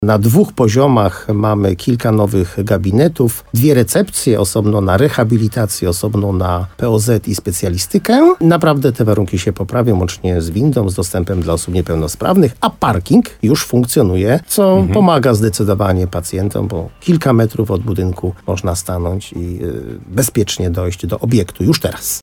Budowa w formule zaprojektuj – wybuduj rozpoczęła się w lutym 2024 roku i jak przyznaje wójt gminy Moszczenica Jerzy Wałęga, została zakończona bez komplikacji w bardzo szybkim tempie.